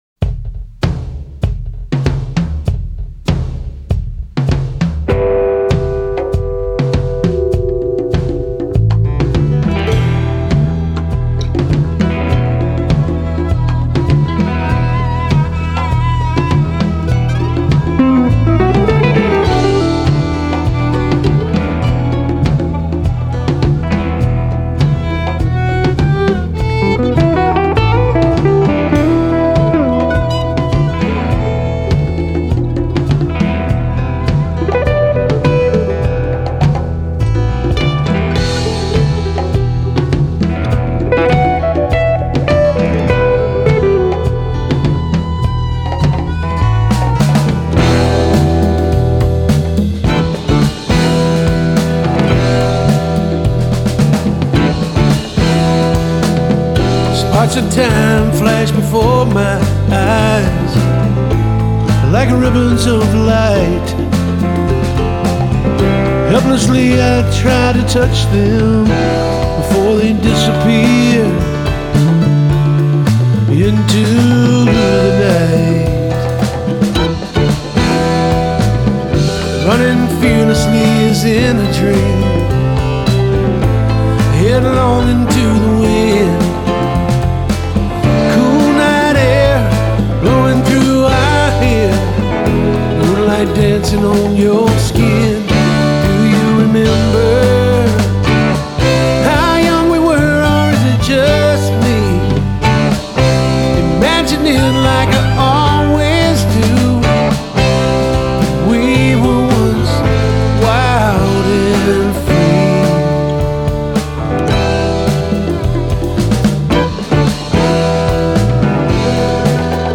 roots music with doses of intricate jazzy passages.